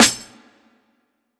TC SNARE 15.wav